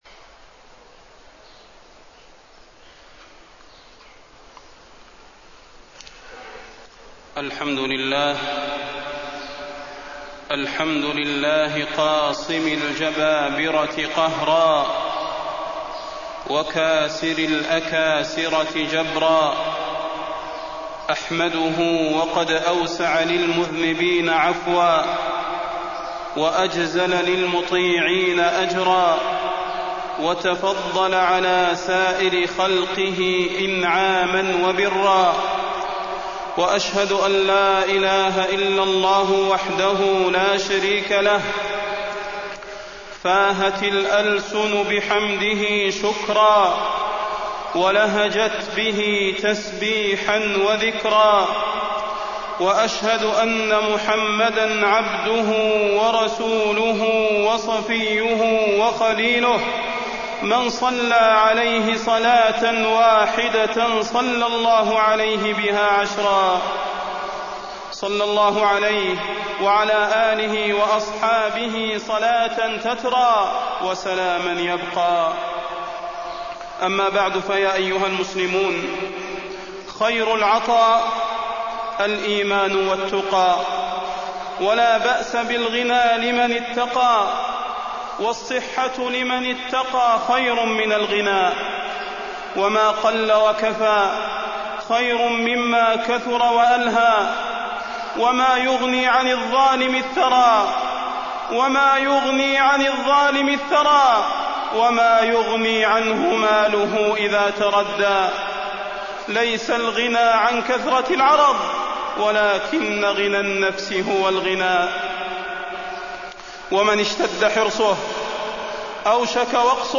تاريخ النشر ٢٣ محرم ١٤٢٦ هـ المكان: المسجد النبوي الشيخ: فضيلة الشيخ د. صلاح بن محمد البدير فضيلة الشيخ د. صلاح بن محمد البدير الظلم وحقوق العمال